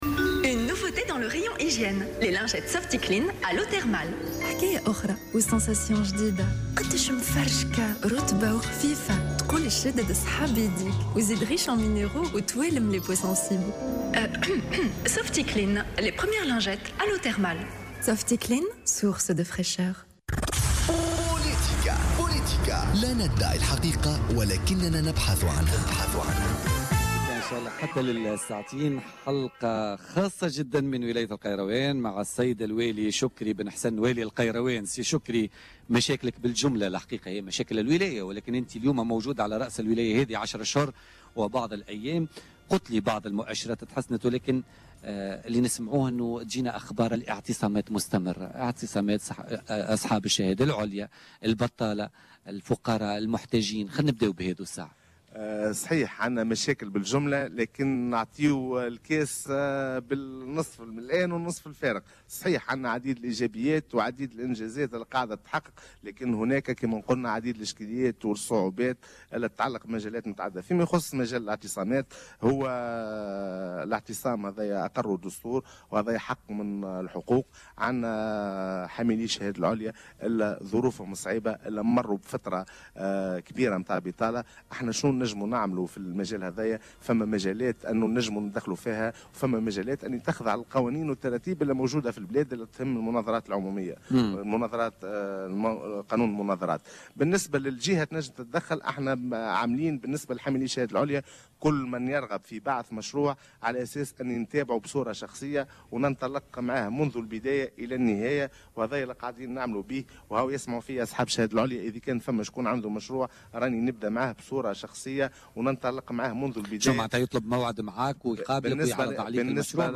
أكد والي القيروان شكري بن حسن ضيف الحصة الخاصة لبوليتيكا اليوم الخميس 18 فيفري من القيروان القيام بحملات للقضاء على الإنتصاب الفوضوي ببعض الأماكن بالمدينة موضحا أنه سيتم توفير فضاءات بديلة للقضاء على هذه الظاهرة تدريجيا وتقنينها.